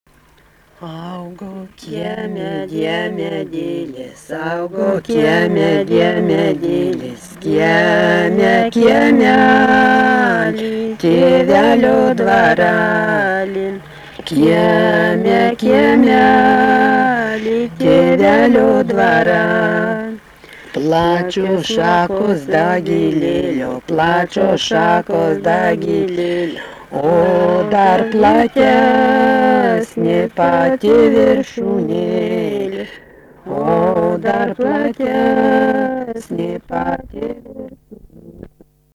daina
Rudnia
vokalinis
Ne visa daina. 2 balsai